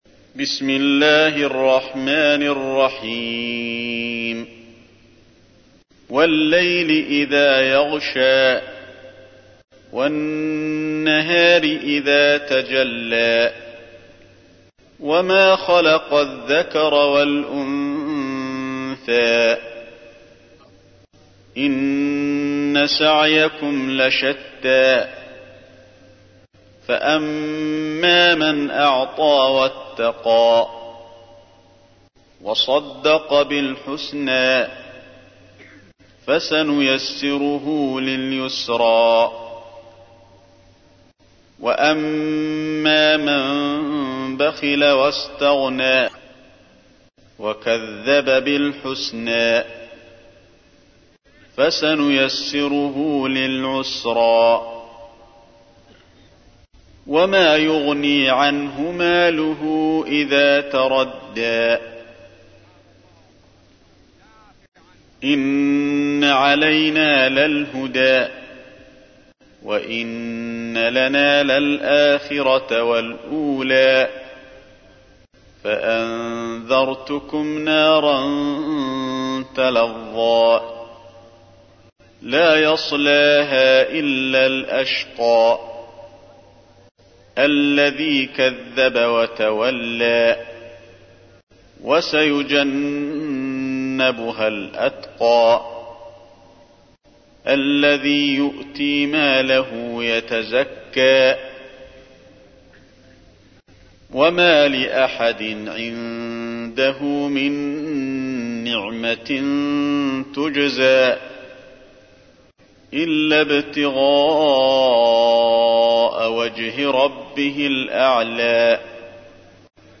تحميل : 92. سورة الليل / القارئ علي الحذيفي / القرآن الكريم / موقع يا حسين